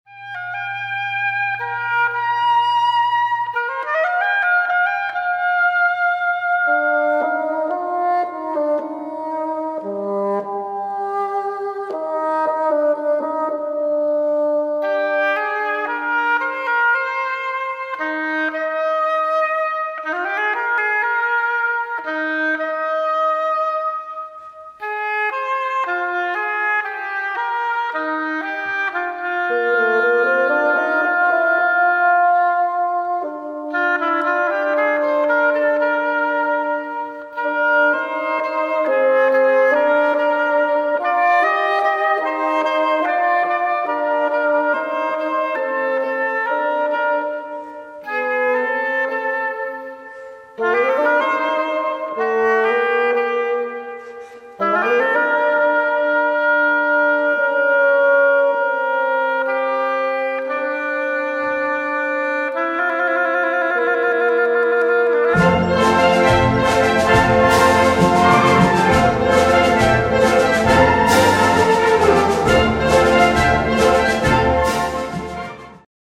Besetzung: Blasorchester
Solo für Oboe und Fagott.